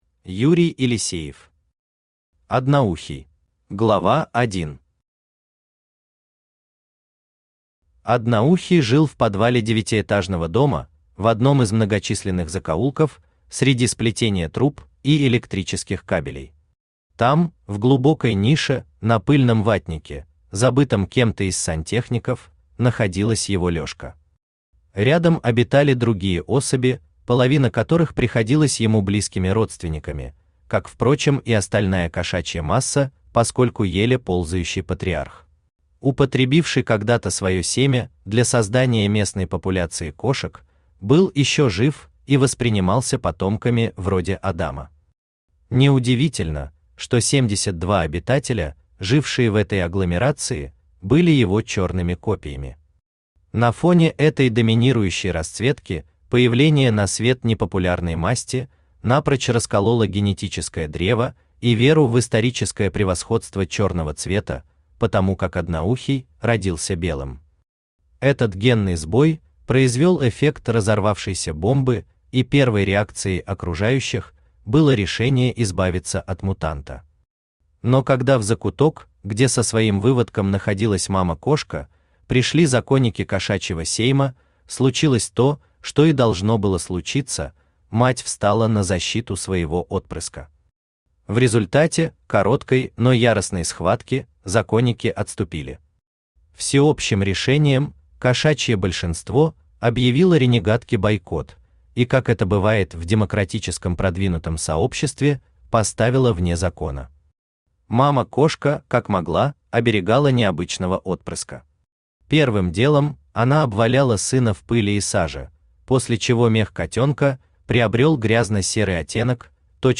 Aудиокнига Одноухий Автор Юрий Павлович Елисеев Читает аудиокнигу Авточтец ЛитРес.